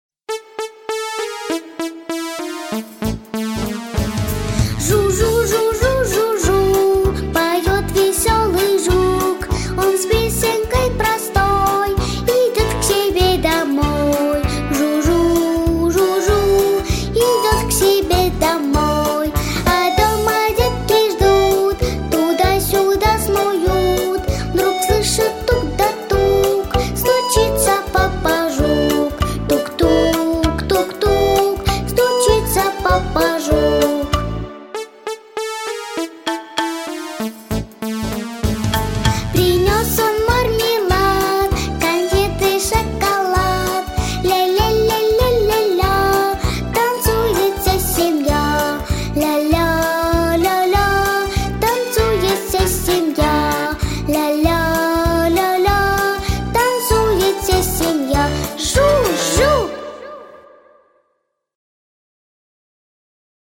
• Категория: Детские песни